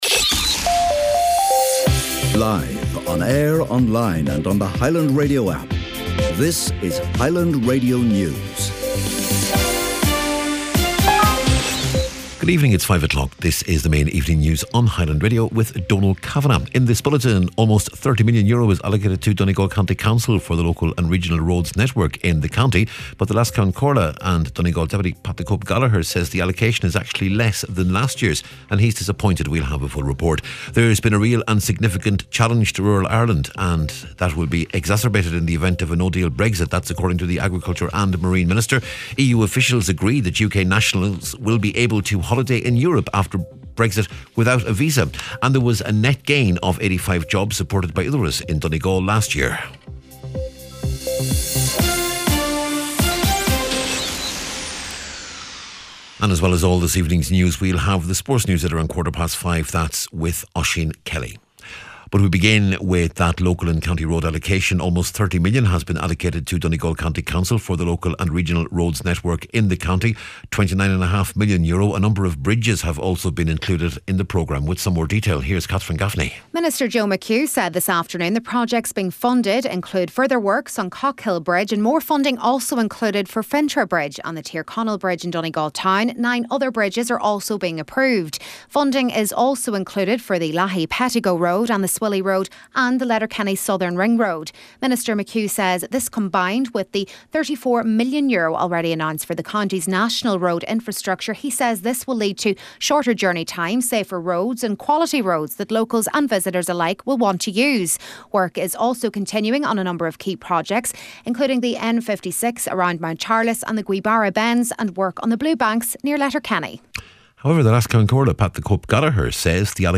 Main Evening News, Sport, and Obituaries Friday February 2nd 2019